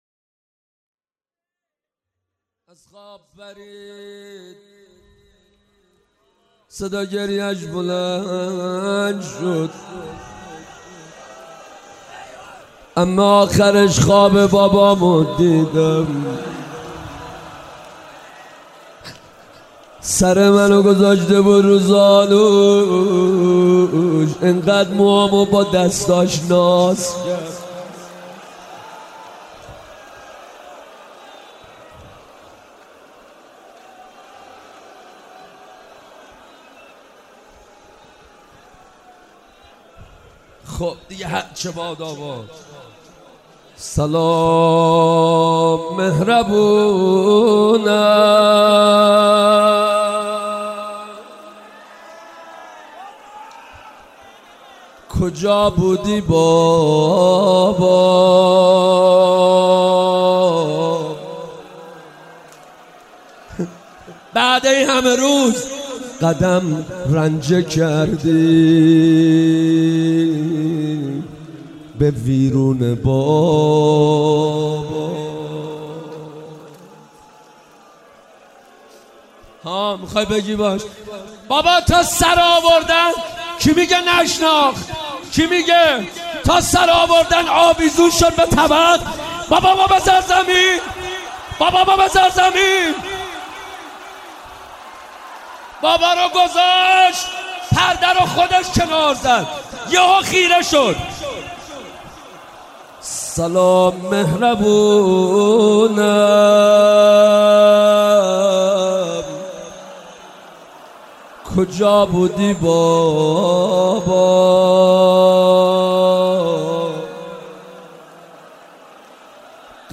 مناسبت : شب سوم محرم
قالب : روضه